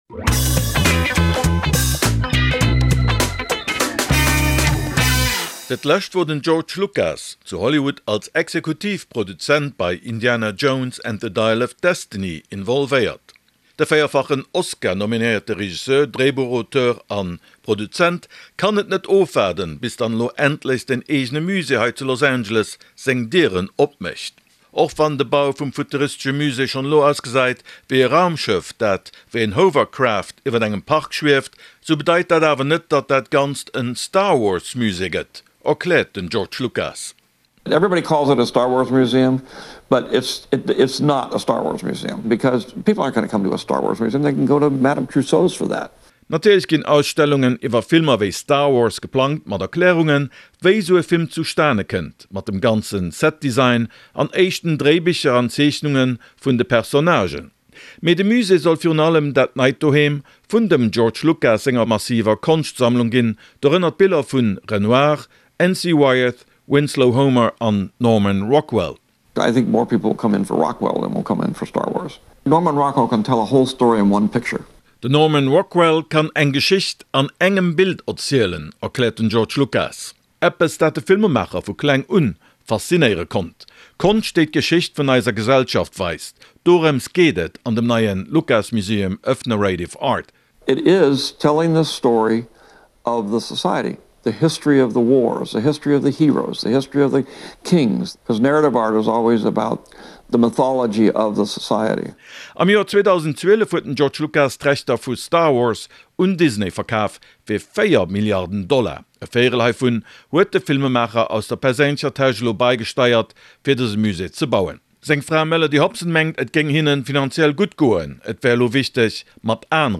huet mam US-Kult-Regisseur a Produzent geschwat.